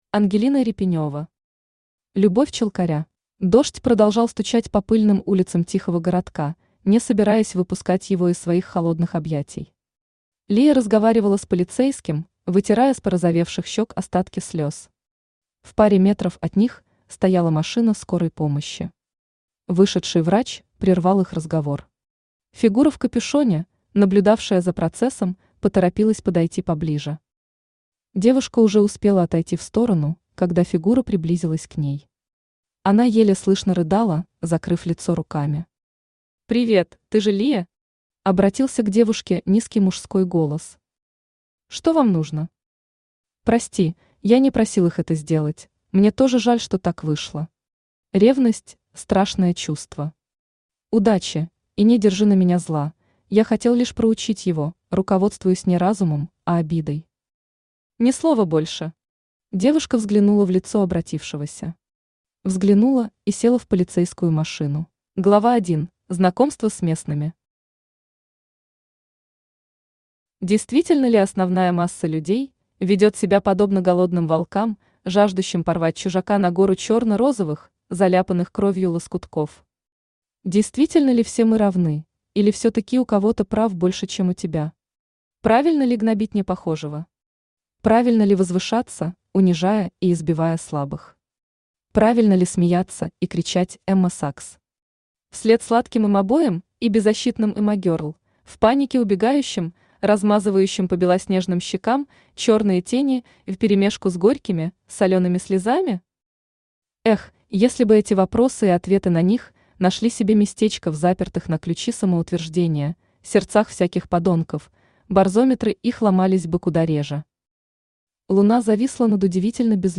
Аудиокнига Любовь челкаря | Библиотека аудиокниг
Aудиокнига Любовь челкаря Автор Ангелина Репенева Читает аудиокнигу Авточтец ЛитРес.